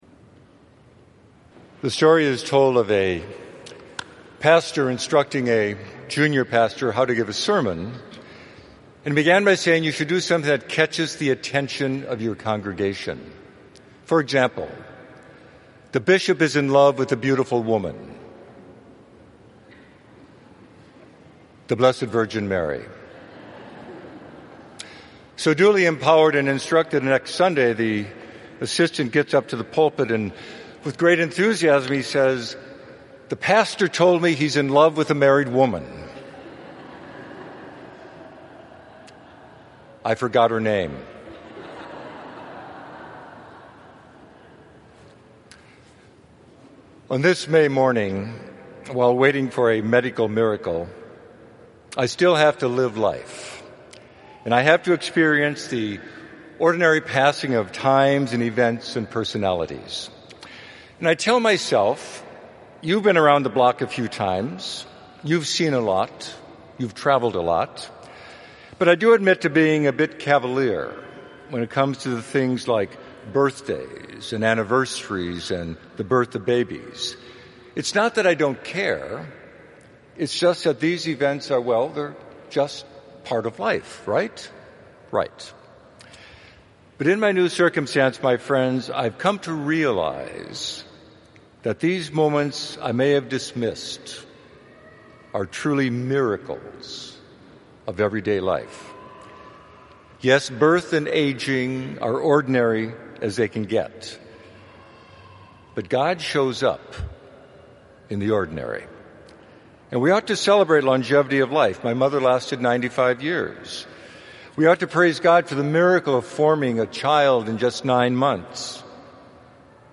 Sixth Sunday of Easter - 9:00 am Celebration